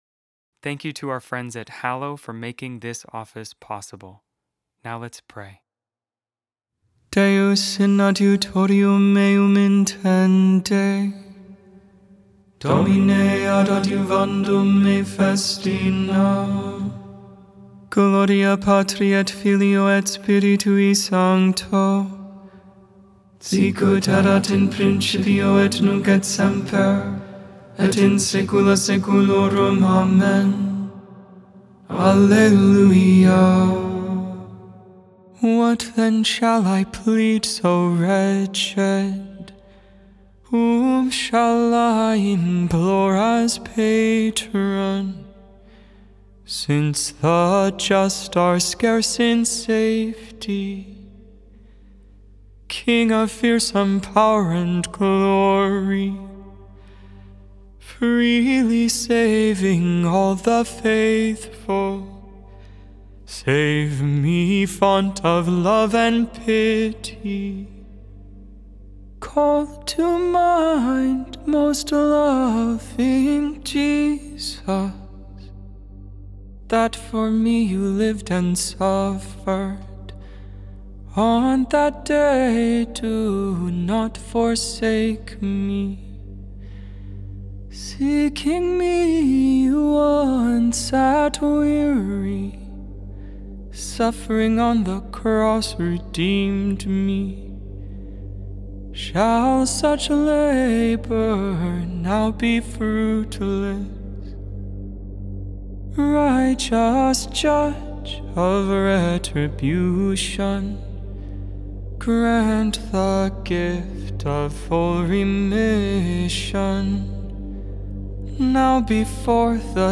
Lauds, Morning Prayer for the 34th Wednesday in Ordinary Time, November 26, 2025.Made without AI. 100% human vocals, 100% real prayer.